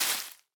Minecraft Version Minecraft Version snapshot Latest Release | Latest Snapshot snapshot / assets / minecraft / sounds / block / leaf_litter / break3.ogg Compare With Compare With Latest Release | Latest Snapshot